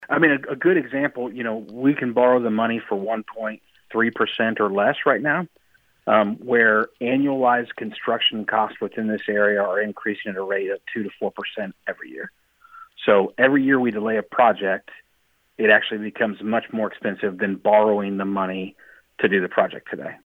Emporia Mayor Rob Gilligan explains the SRF fund is a great cost-saving resource for the city.